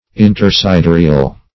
Search Result for " intersidereal" : The Collaborative International Dictionary of English v.0.48: Intersidereal \In`ter*si*de"re*al\, a. Between or among constellations or stars; interstellar.